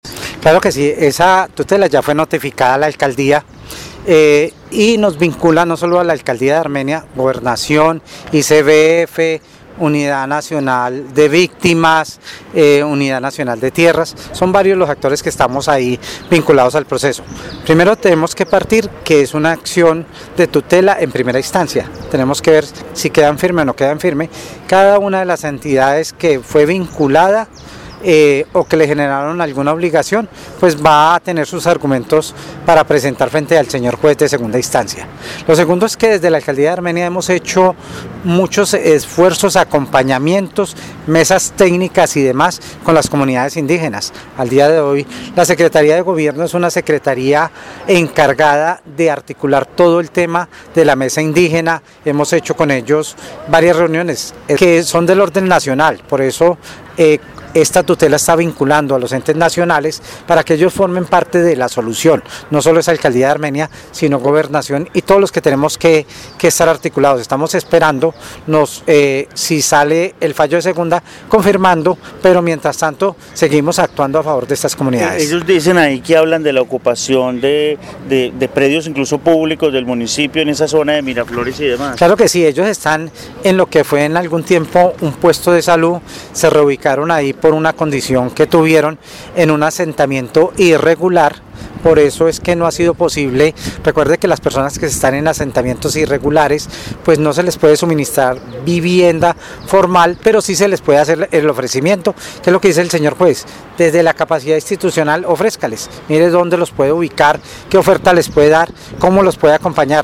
Carlos Arturo Ramírez, secretaria de salud